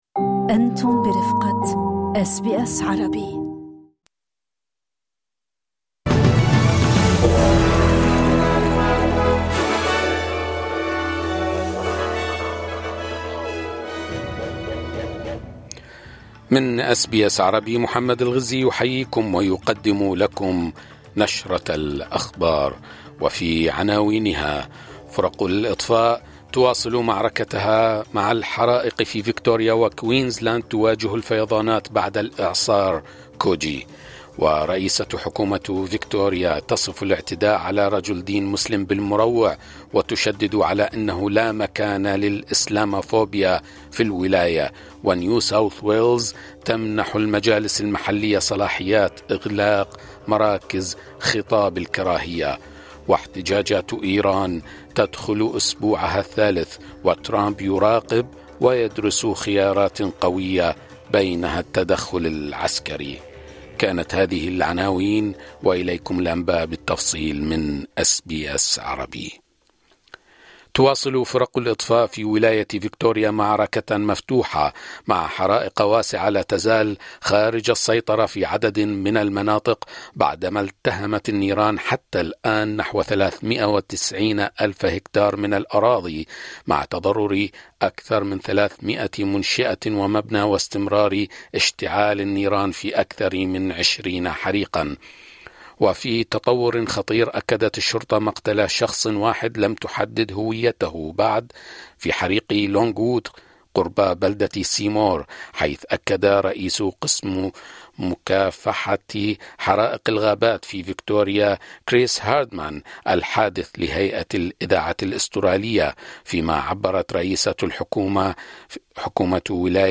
نشرة أخبار المساء 12/01/2026